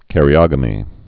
(kărē-ŏgə-mē)